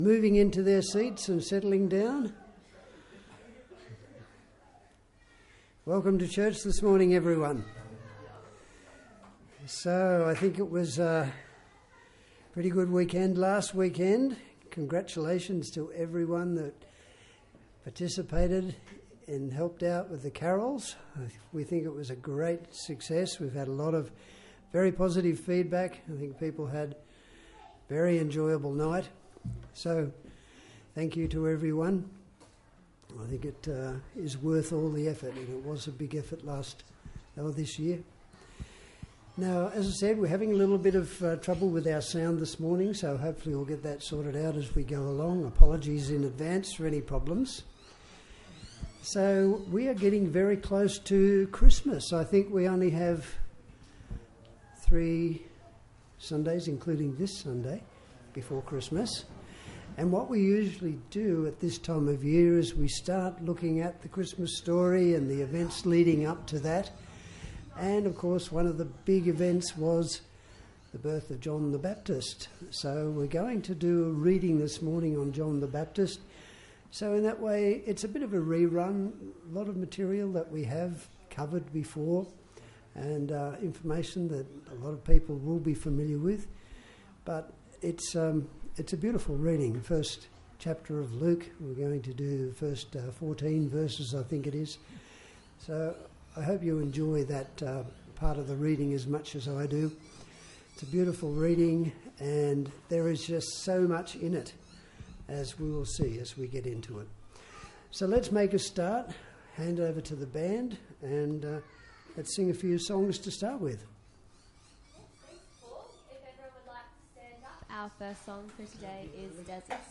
Passage: Luke 1:1-25 Service Type: Sunday Church